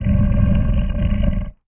Sfx_creature_spikeytrap_idle_os_07.ogg